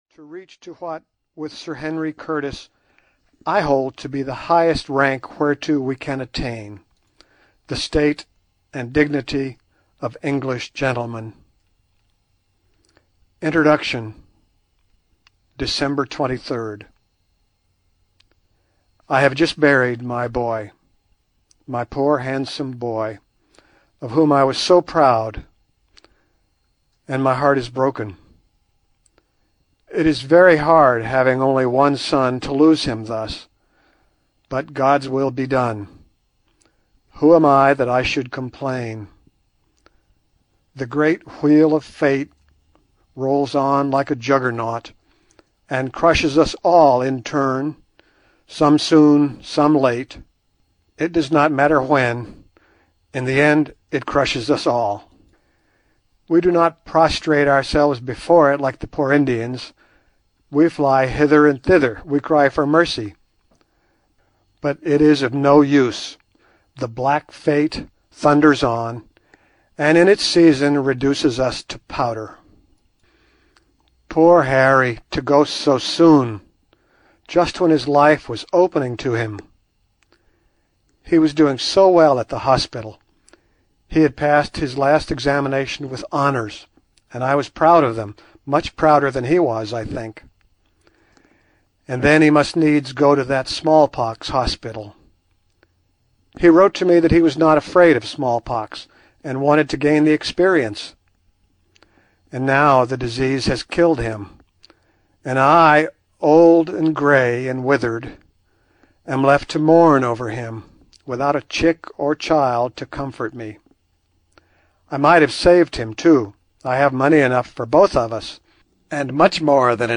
Allan Quartermain (EN) audiokniha
Ukázka z knihy